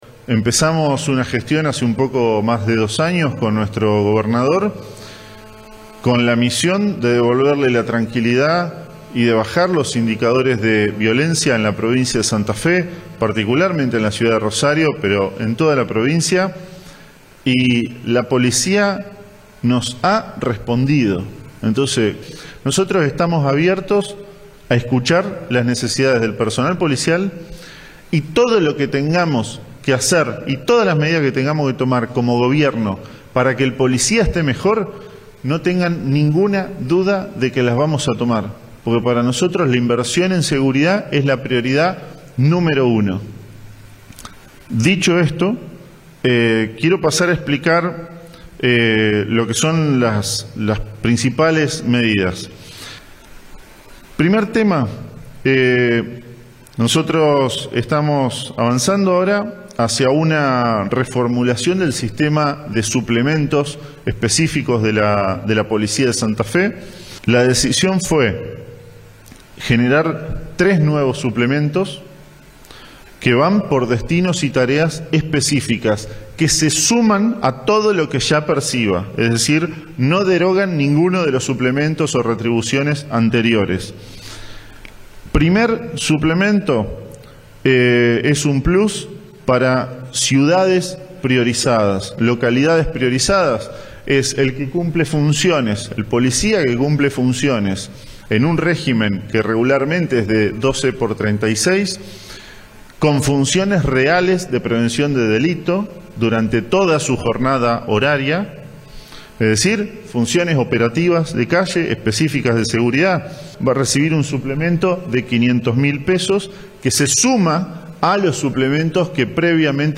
Fragmentos de la presentación a cargo de Cococcioni